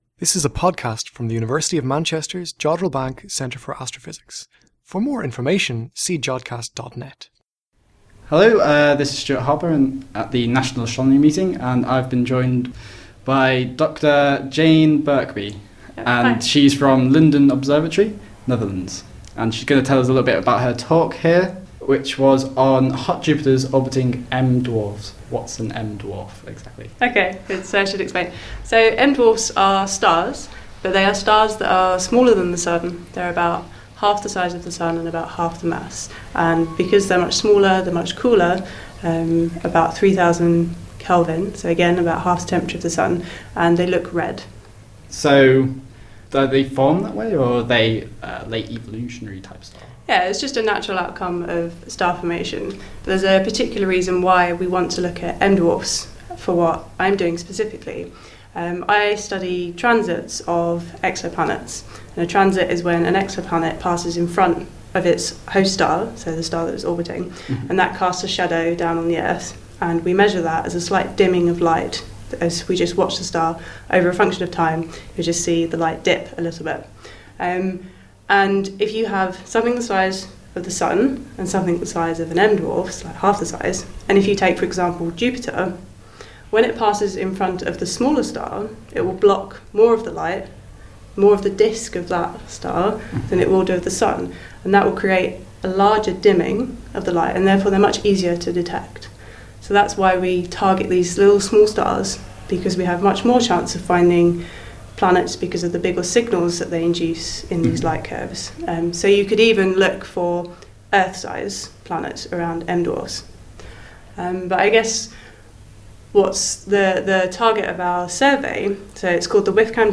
In this month's show we bring you the first of many interviews recorded at the National Astronomy Meeting.
Interview